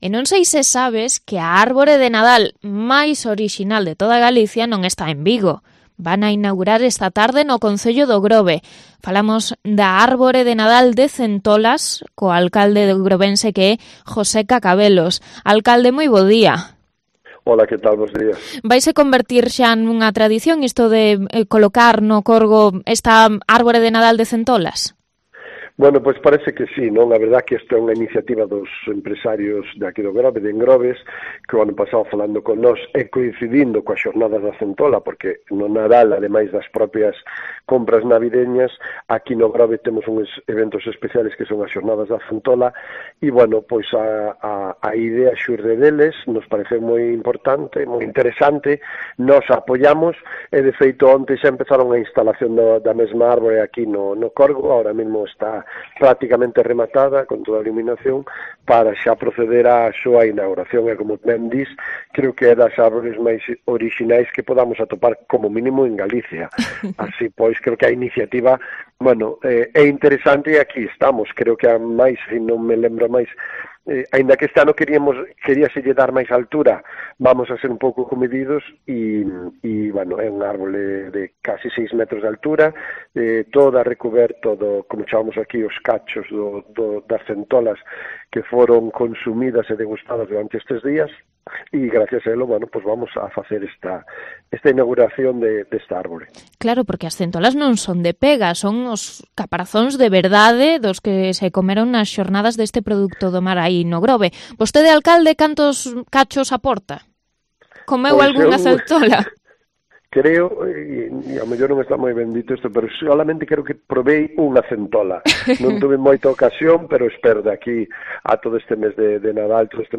Entrevista al alcalde de O Grove sobre su particular árbol navideño